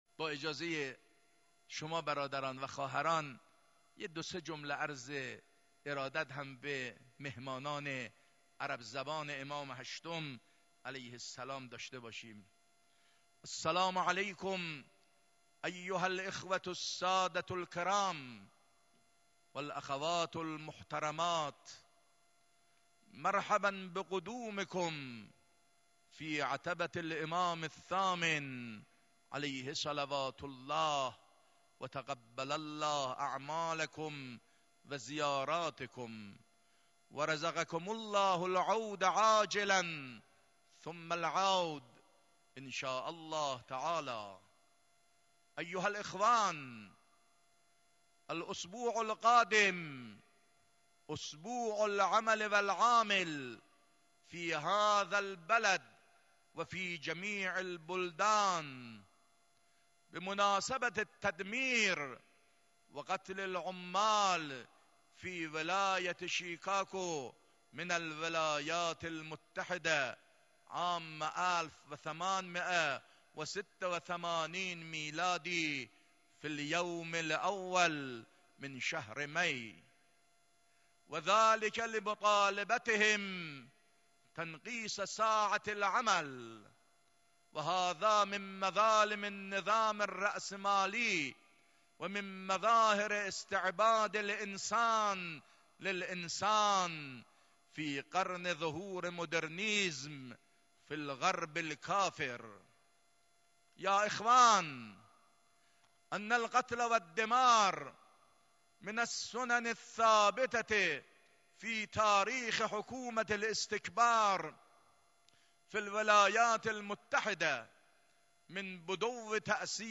خطبه عربی 5.2.92.mp3